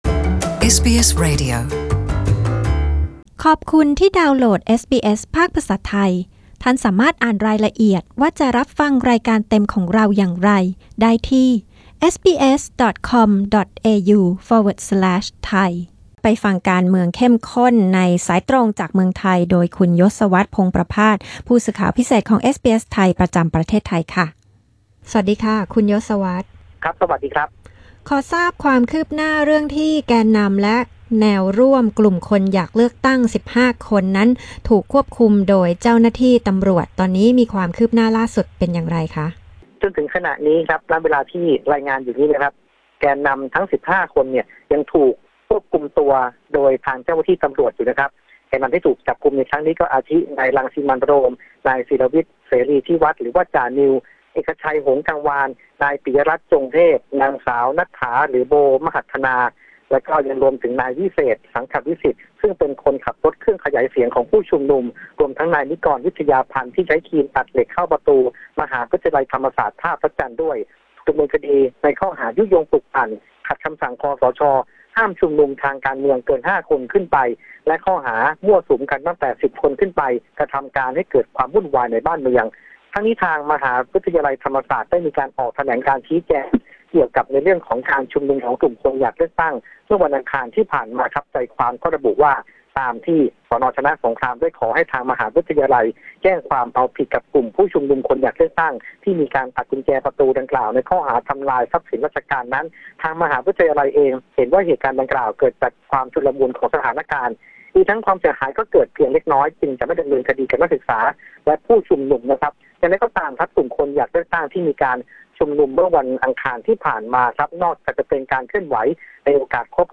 รายงานข่าวสายตรงจากเมืองไทย 24 พ.ค. 2018